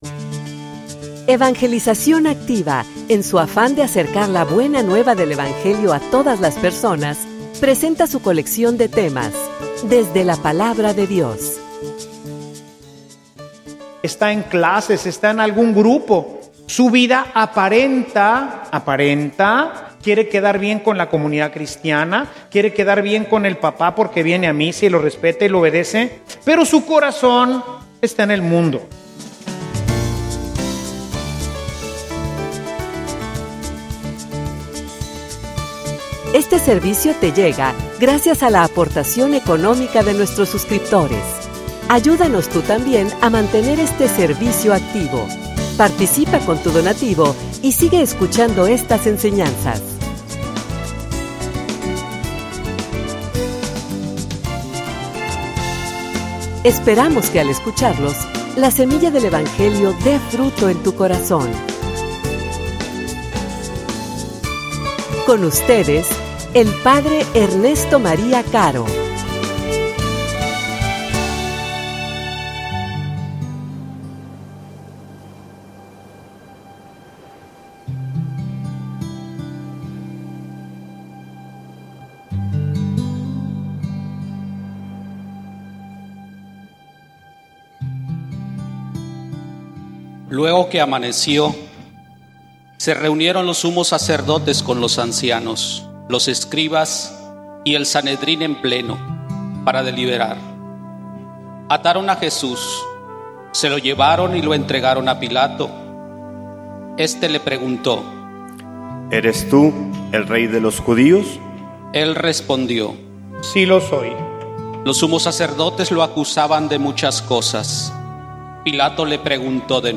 homilia_Cristiano_asume_tu_responsabilidad.mp3